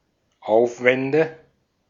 Ääntäminen
Ääntäminen Tuntematon aksentti: IPA: /ˈʔaʊ̯fvɛndə/ Haettu sana löytyi näillä lähdekielillä: saksa Käännöksiä ei löytynyt valitulle kohdekielelle. Aufwände on sanan Aufwand monikko.